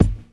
Kicks